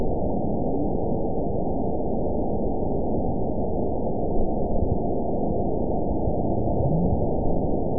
event 919888 date 01/28/24 time 00:49:43 GMT (1 year, 10 months ago) score 9.10 location TSS-AB03 detected by nrw target species NRW annotations +NRW Spectrogram: Frequency (kHz) vs. Time (s) audio not available .wav